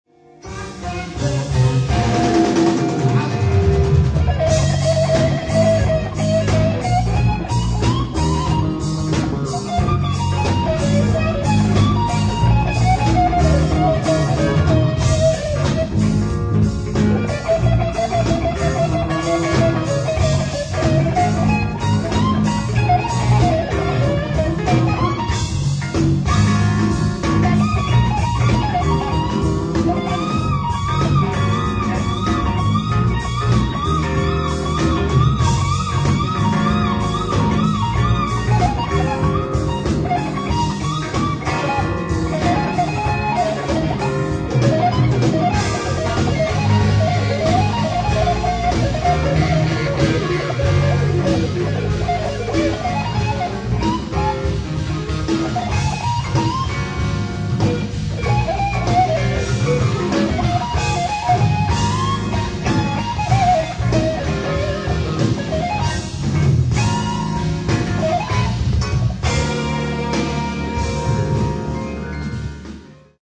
ライブ・アット・フランクフルト、ドイツ
※試聴用に実際より音質を落としています。